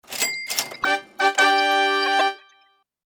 Terminal Sound
World Lottery Association terminals will play a winning sound when you validate a winning Draw game or Scratch-Off ticket. This audible tone will offer an added level of protection by letting the retailers and consumers know the ticket is a winner.
Register_Horn.mp3